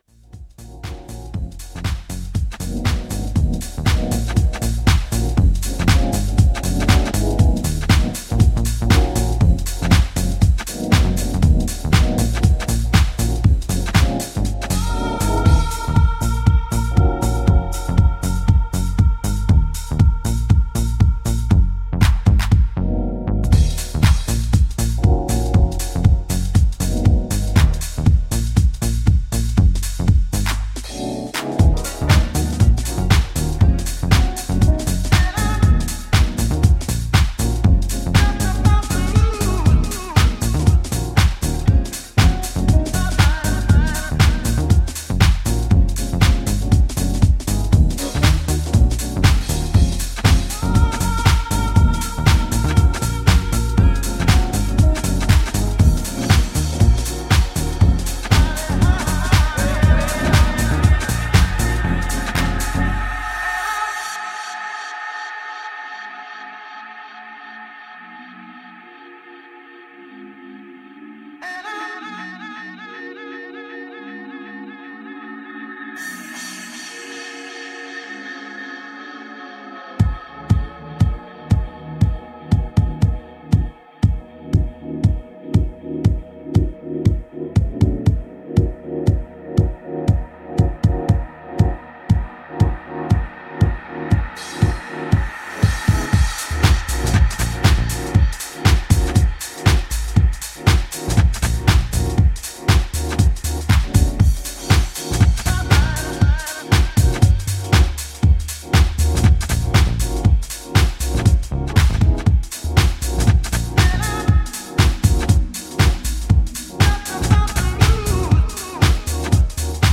US-style deep house tracks